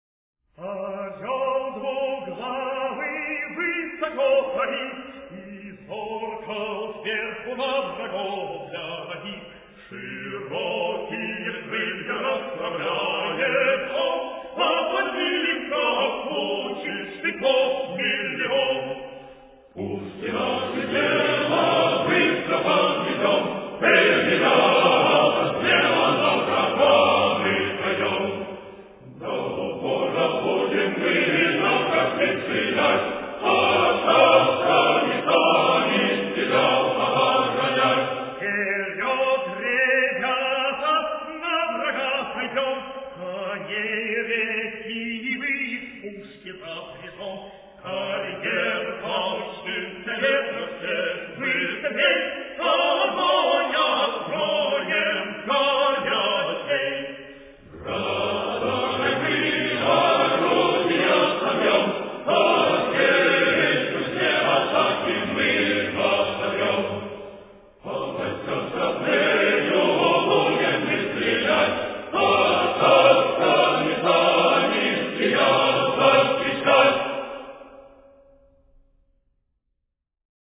Орел Двуглавый высоко парит. Песня Лейб-гвардии Конной артиллерии прослушать песню в исполнении хора Валаамского монастыря ...>>> Орёл двуглавый высоко парит И зорко сверху на врагов глядит.